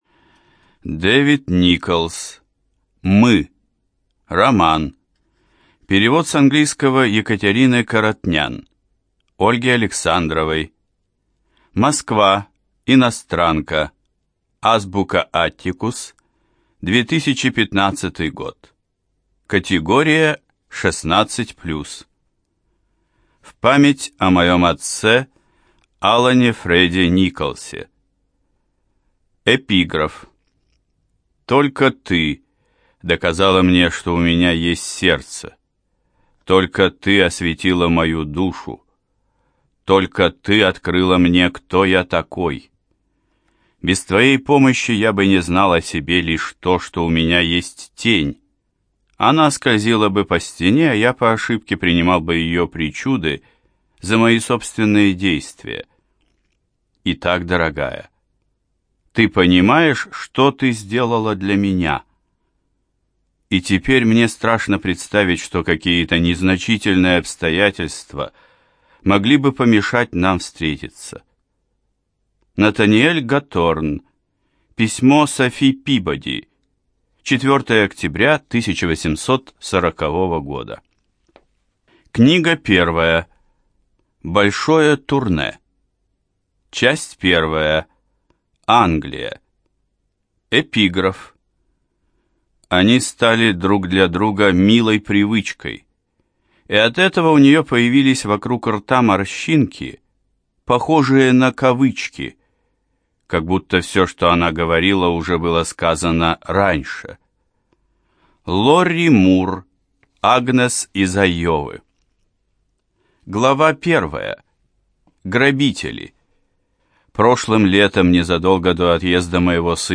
ЖанрСовременная проза
Студия звукозаписиЛогосвос